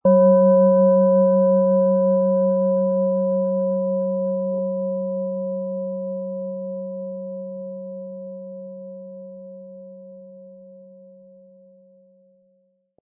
OM Ton
Die Klangschale wurde von erfahrenen Fachkräften in jahrhundertealter Tradition in Handarbeit gefertigt.
Im Sound-Player - Jetzt reinhören können Sie den Original-Ton genau dieser Schale anhören.
SchalenformBihar
MaterialBronze